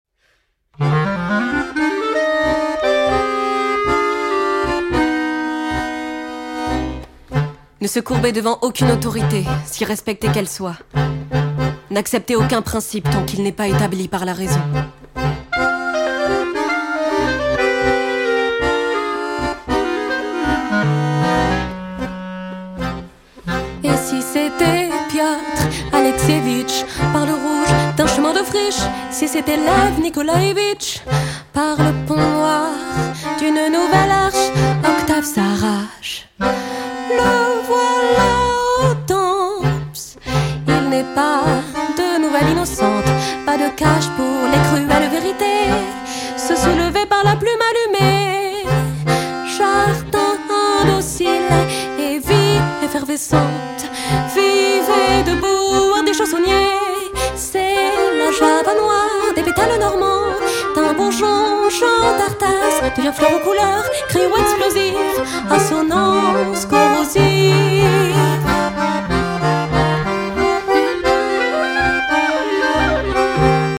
clarinette, cor de basset